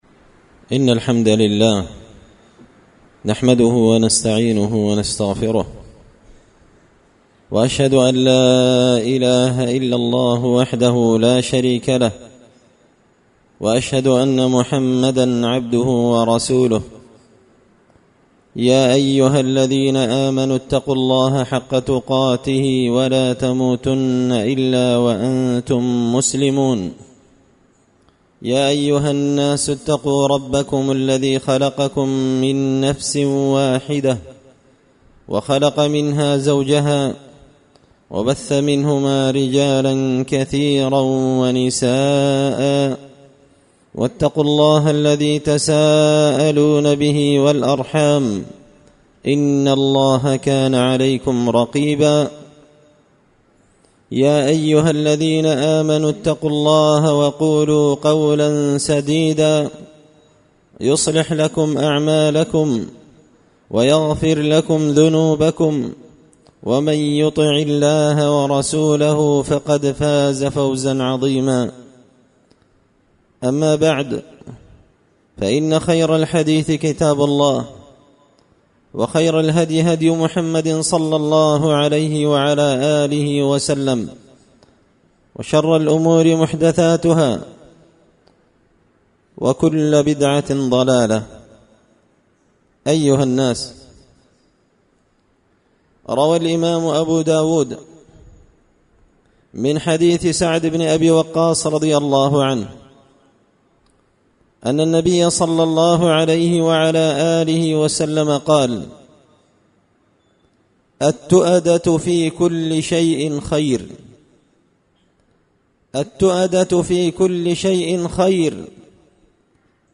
خطبة جمعة بعنوان – التؤدة في كل شيئ خير إلا في عمل الآخرة
دار الحديث بمسجد الفرقان ـ قشن ـ المهرة ـ اليمن